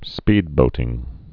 (spēdbōtĭng)